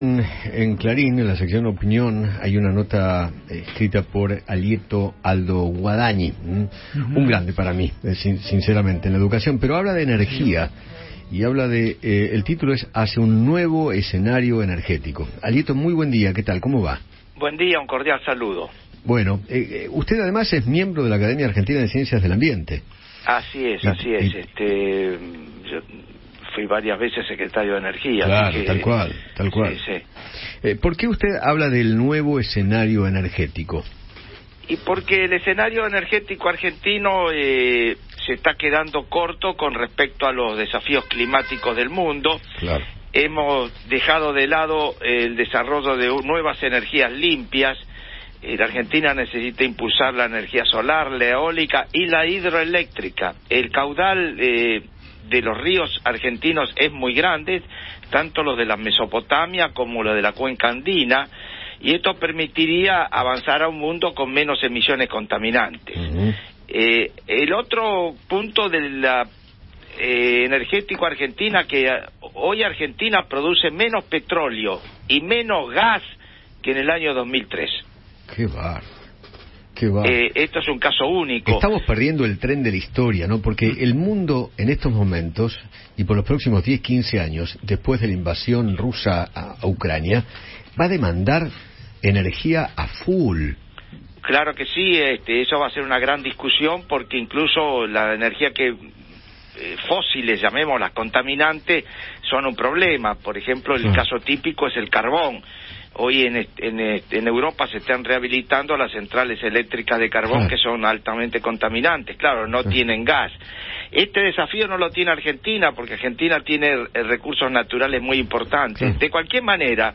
Alieto Guadagni, miembro de la Academia de Ciencias del Ambiente, conversó con Eduardo Feinmann sobre el escenario energético de la Argentina.